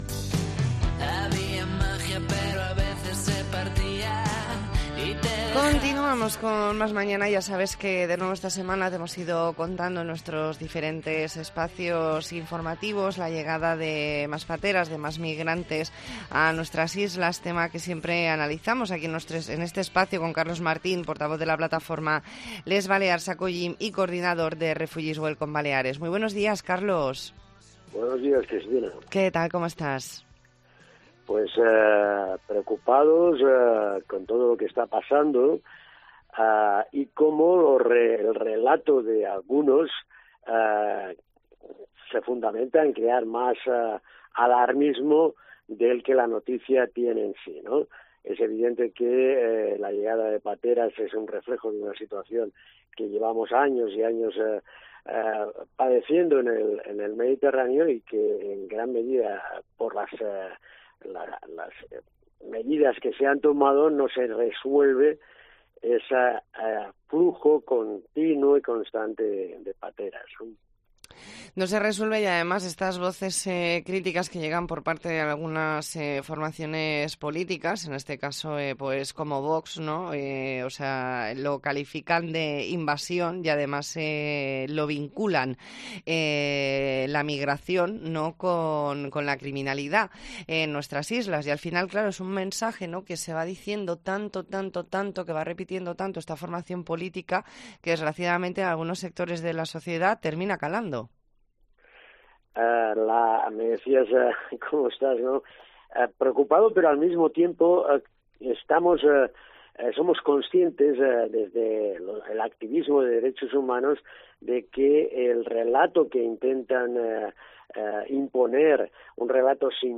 E ntrevista en La Mañana en COPE Más Mallorca, martes 11 de octubre de 2022.